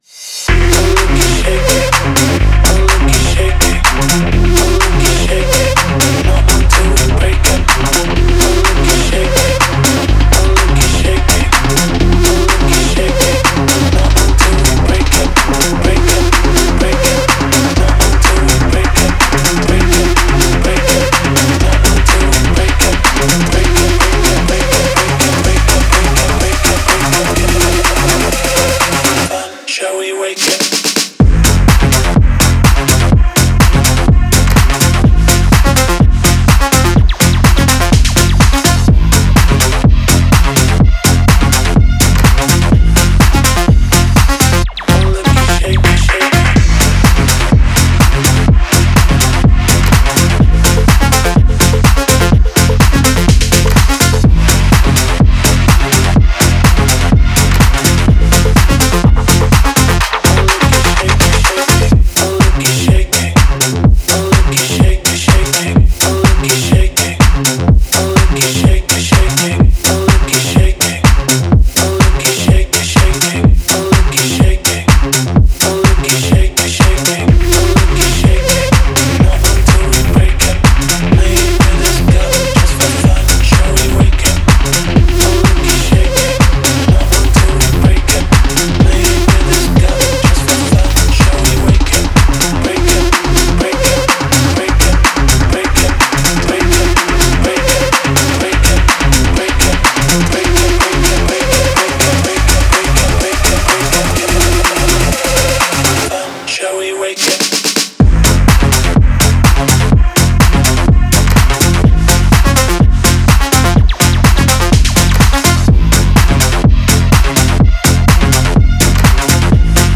насыщено синтезаторами и глубокими басами